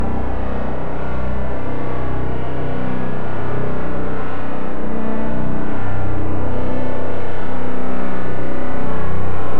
• Robotic Space texture 100bpm.wav
Robotic_Space_texture_100bpm__91L.wav